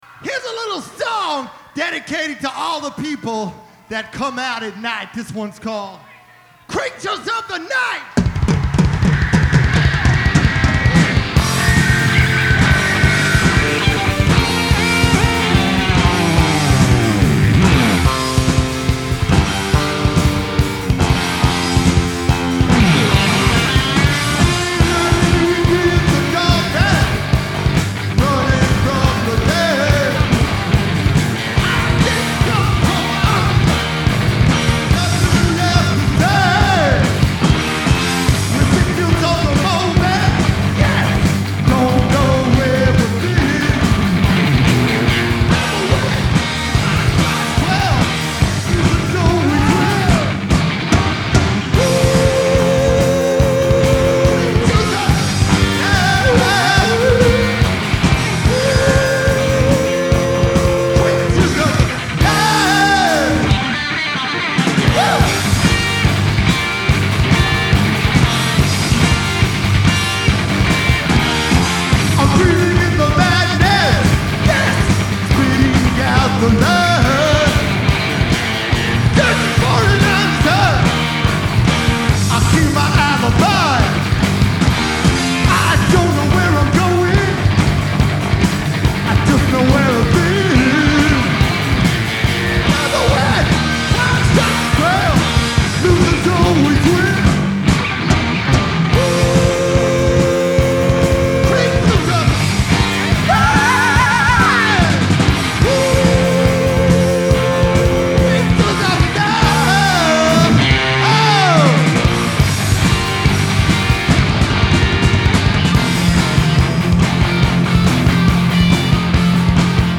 Genre : Rock
Live From Mid-Hudson Civic Arena, Poughkeepsie NY